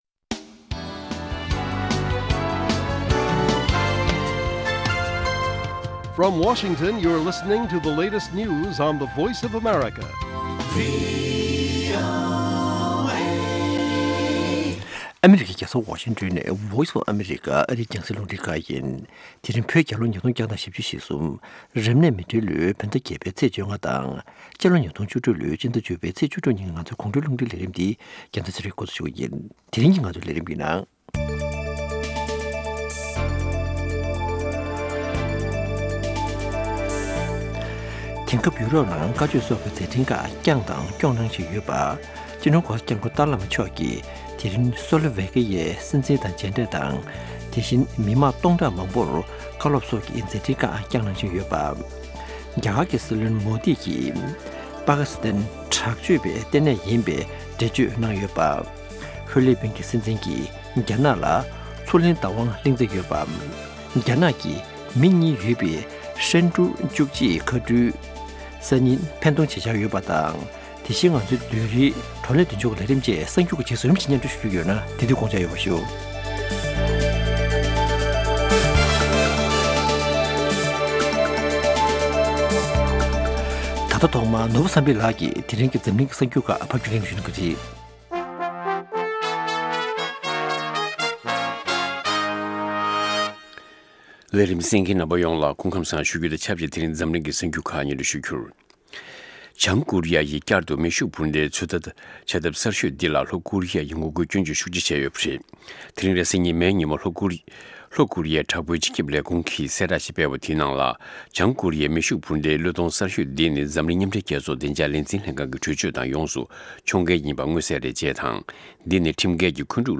Good Evening Tibet Broadcast daily at 10:00 PM Tibet time, the Evening Show presents the latest regional and world news, correspondent reports, and interviews with various newsmakers and on location informants. Weekly features include Tibetan Current Affairs, Youth, Health, Buddhism and Culture, and shows on traditional and contemporary Tibetan music.